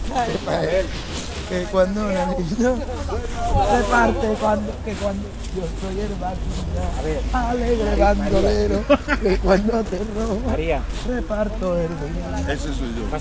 Tavern / Loops / Chatter
chatter-1.ogg